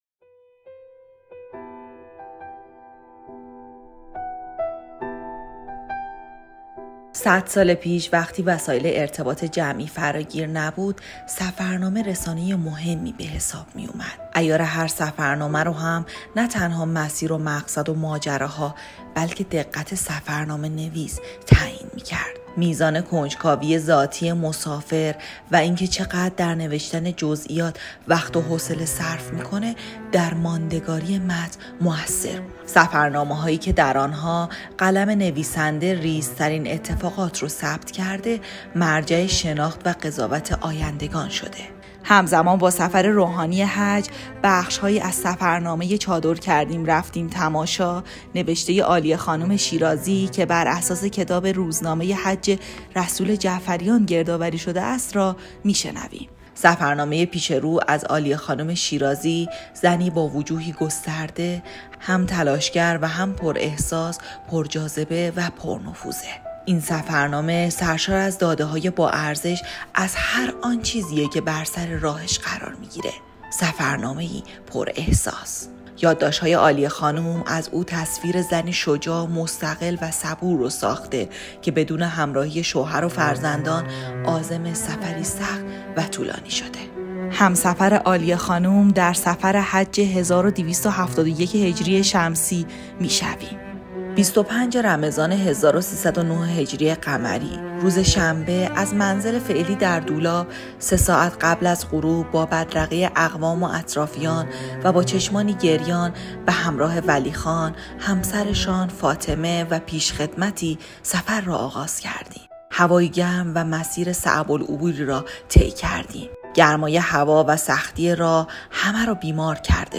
روایت